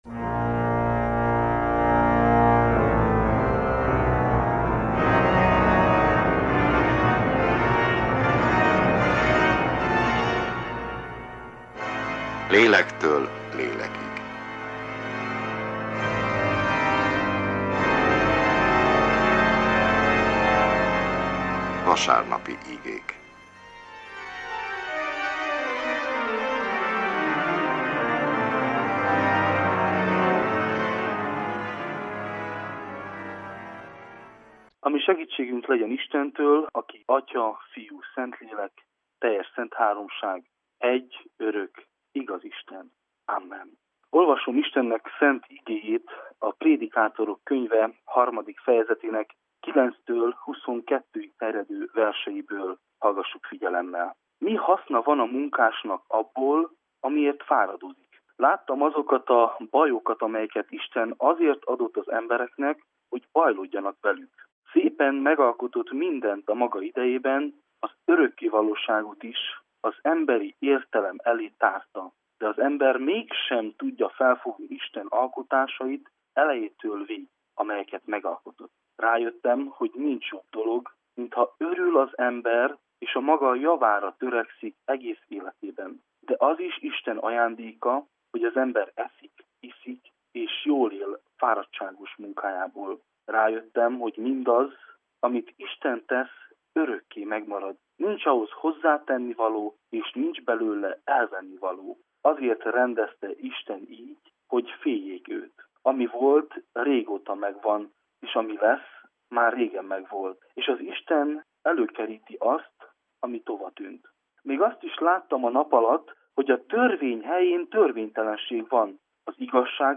Református igehirdetés, január 28.
Egyházi műsor